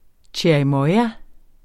Udtale [ tjeɐ̯iˈmʌja ]